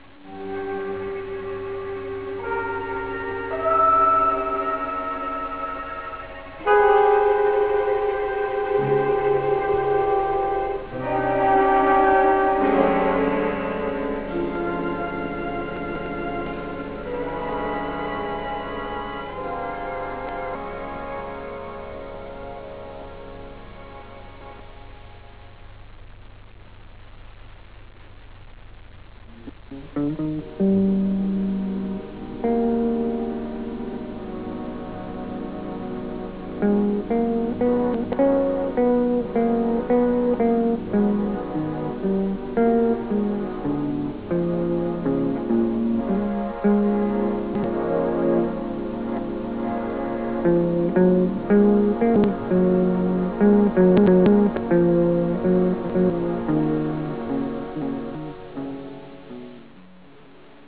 Musica jazz
Original Track Music